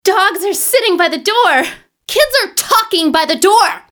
angry.wav